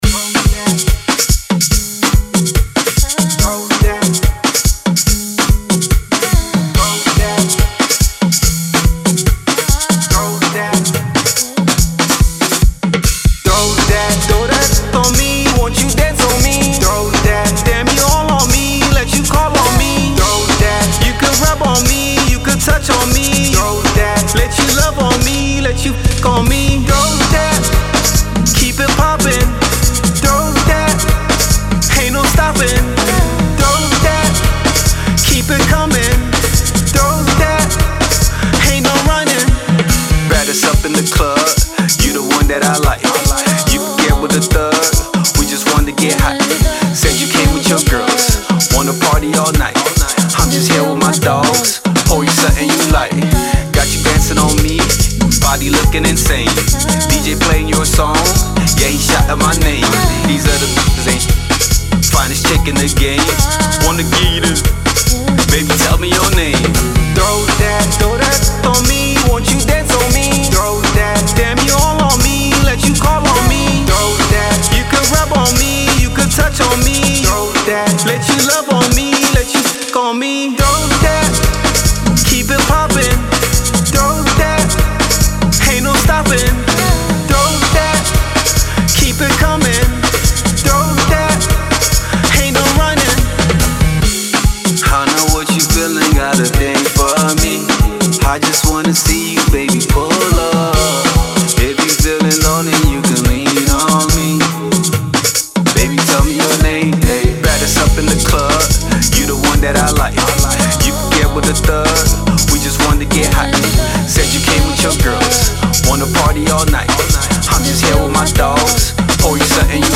Made with a Bouyon style beat
Clean Version